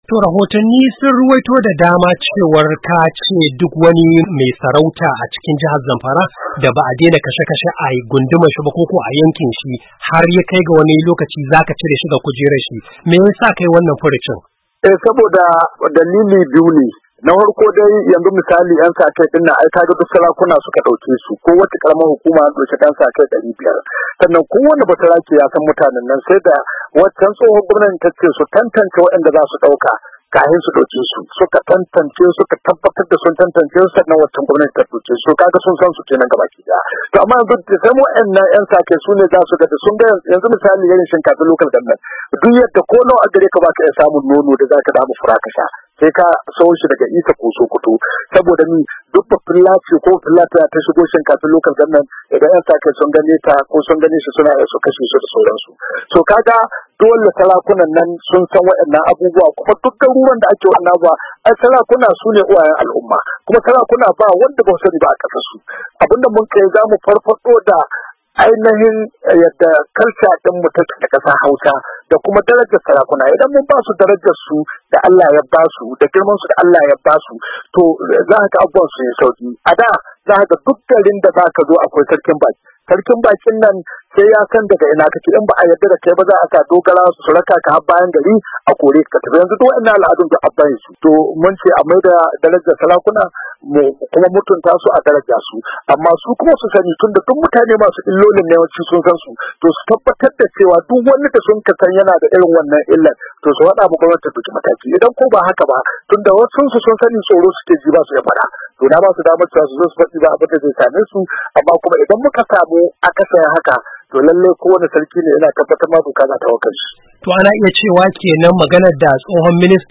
Gwamnan ya bayyana hakan ne yayin wata zantawa da ya yi da Muryar Amurka inda ya ce suna da rahotanni da suka tabbatar masu cewar gwamnatin da ta gabata ta daukin 'yan saka kai a kowacce karamar hukuma, karkashin jagorancin sarakunan yankunan.